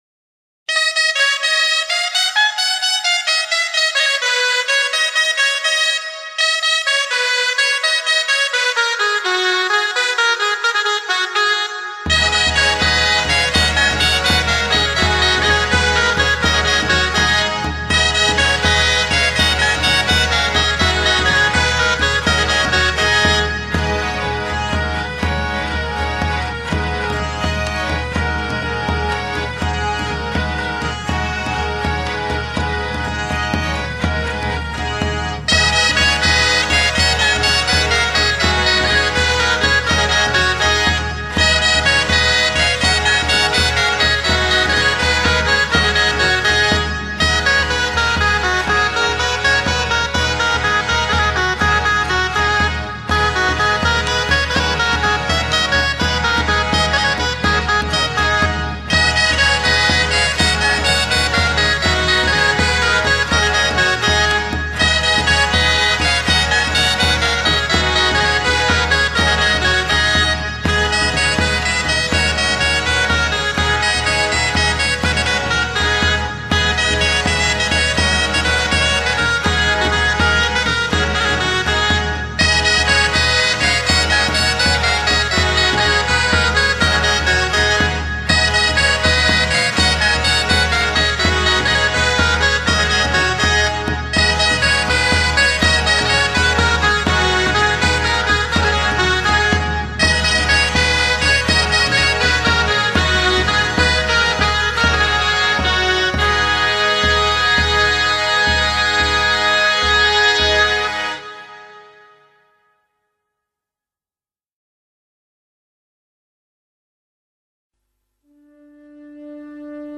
LCyklNZeJa0_Canciones-medievales.mp3